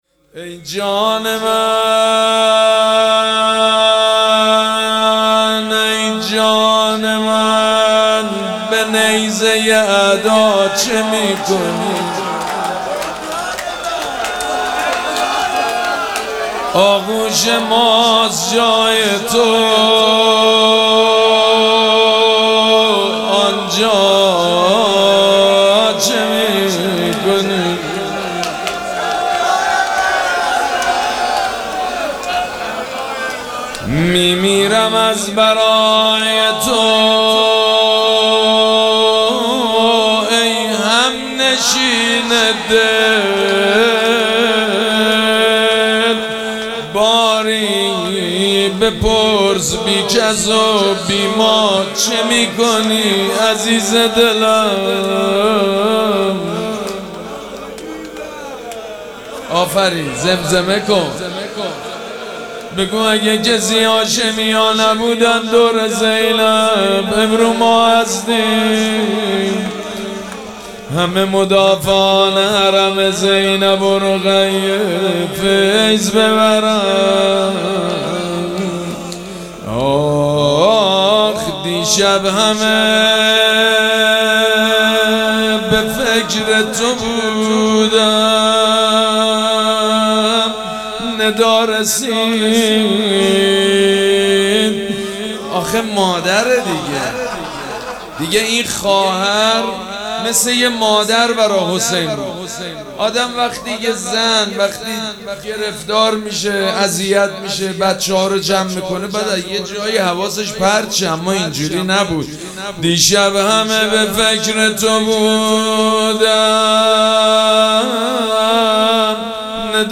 مراسم عزاداری شام شهادت حضرت رقیه سلام الله علیها ‌‌‌‌‌‌‌‌‌‌چهارشنبه ۸ مرداد ۱۴۰۴ | ۵ صفر ۱۴۴۷ ‌‌‌‌‌‌‌‌‌‌‌‌‌هیئت ریحانه الحسین سلام الله علیها
سبک اثــر روضه مداح حاج سید مجید بنی فاطمه